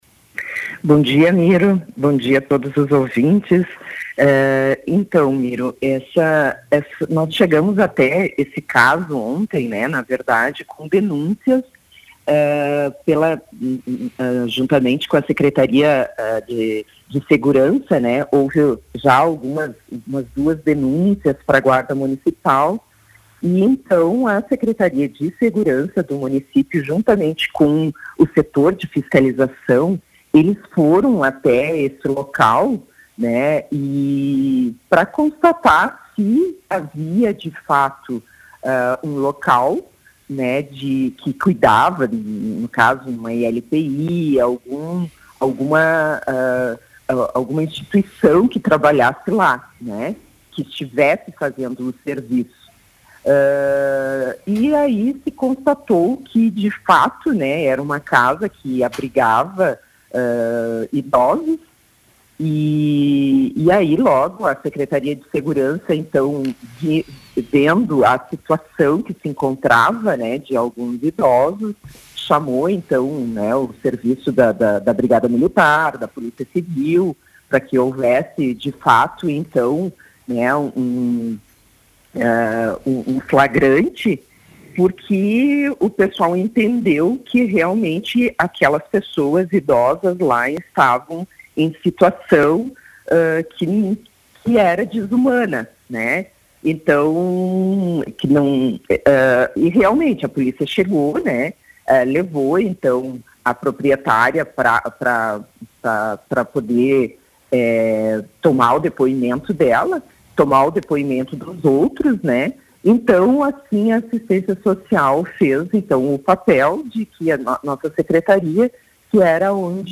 Secretária da Assistência Social diz que estabelecimento atendia idosos de desumana e faz alerta